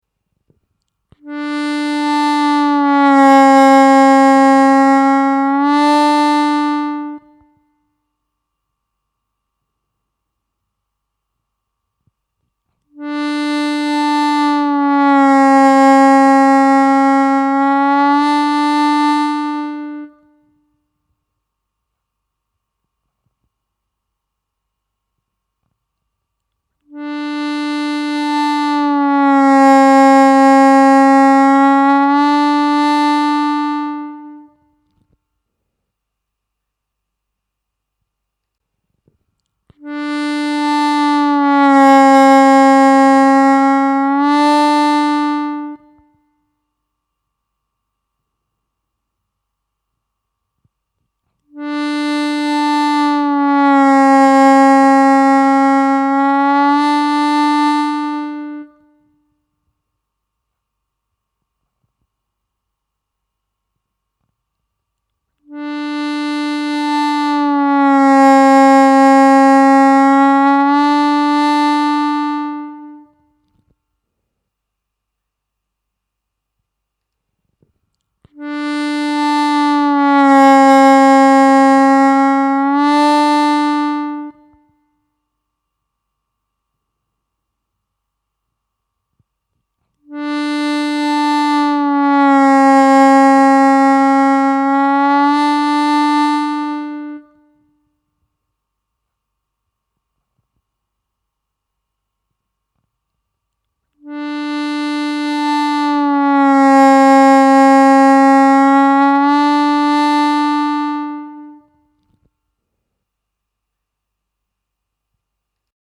Звуковой файл для отработки бенда (в паузах играйте свой бенд на первом отверстии)